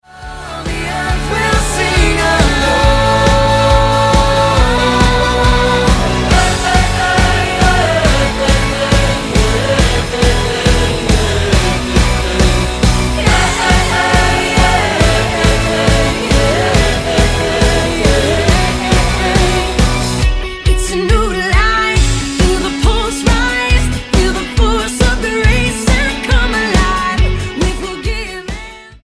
Modern worship band
Style: Rock Approach: Praise & Worship